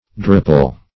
Search Result for " drupel" : The Collaborative International Dictionary of English v.0.48: Drupel \Drup"el\, Drupelet \Drupe"let\, n. [Dim. of Drupe .]